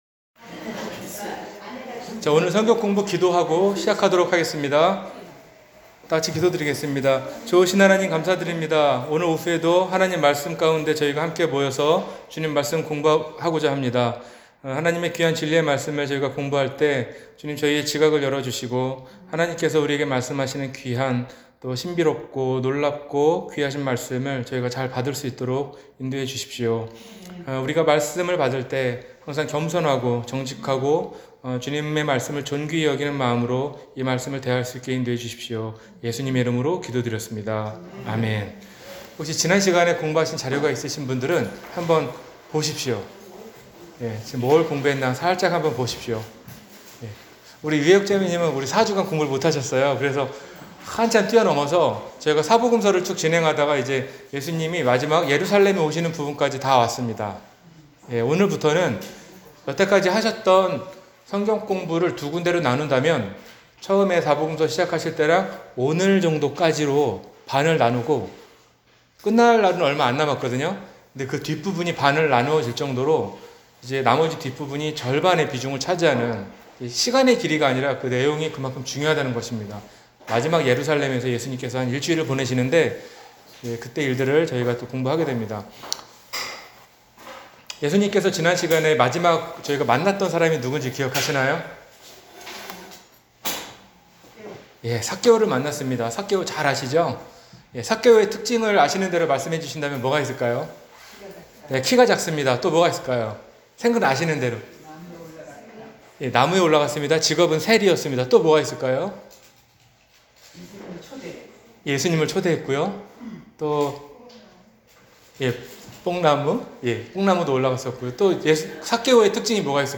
한눈에 보는 성경 17-사복음서 12-주일성경공부